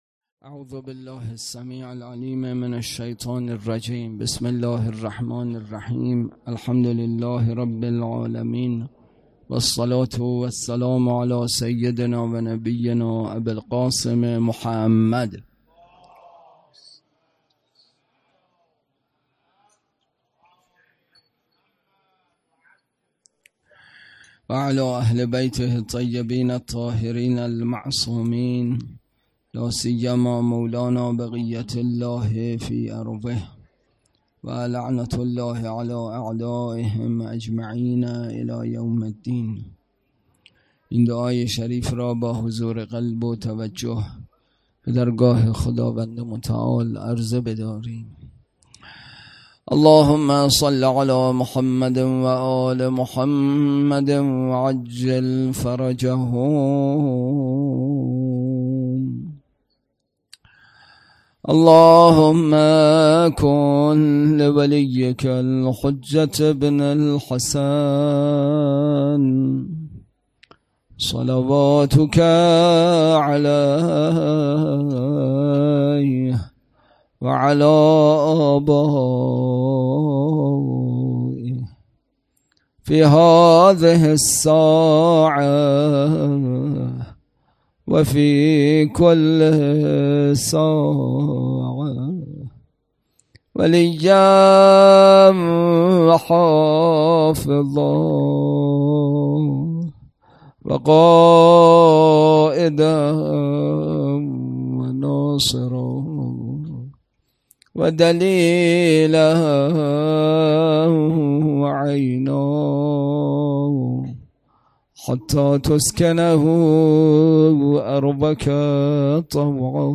سخنرانی
برگزارکننده: مسجد اعظم قلهک